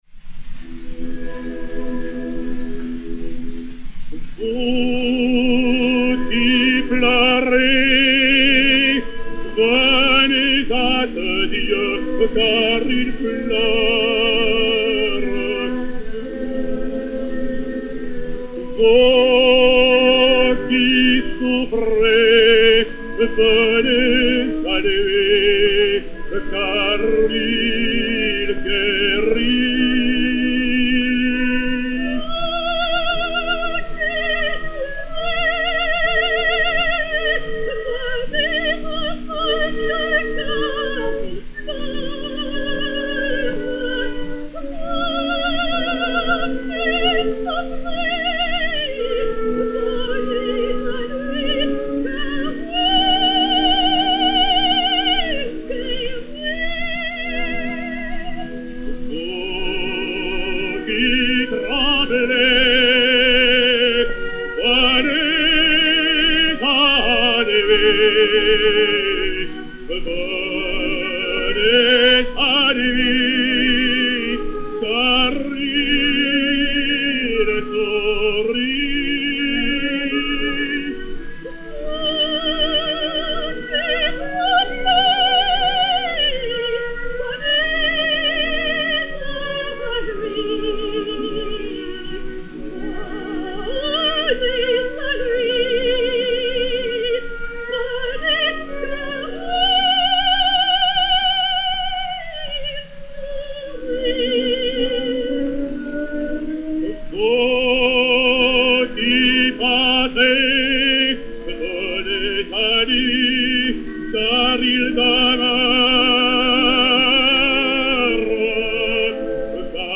Chant religieux à deux voix